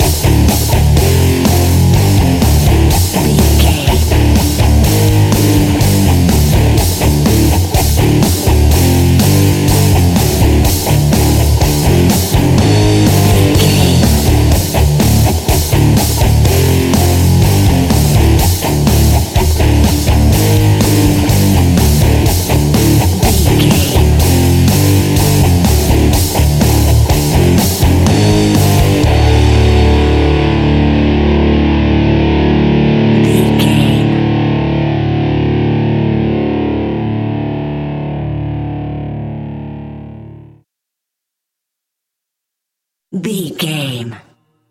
Ionian/Major
energetic
driving
heavy
aggressive
electric guitar
bass guitar
drums
electric organ
hard rock
heavy metal
distortion
heavy drums
distorted guitars
hammond organ